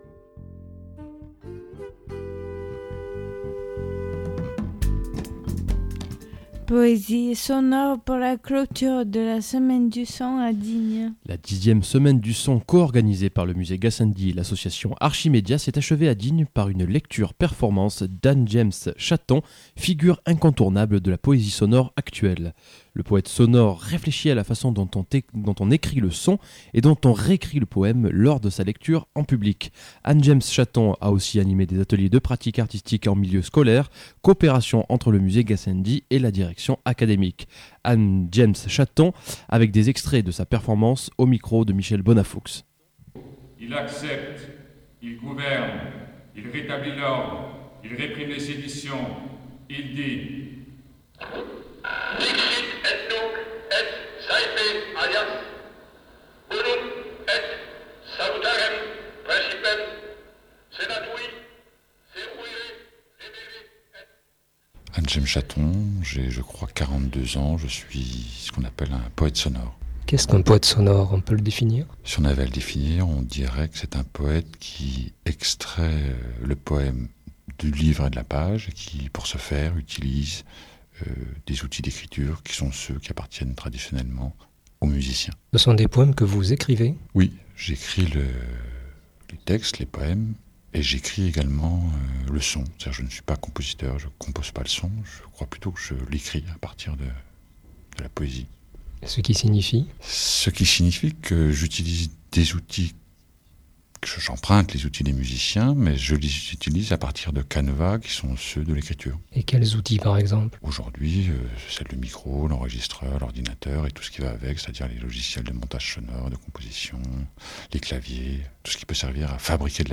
Poésie sonore pour la clôture de la semaine du son à Digne.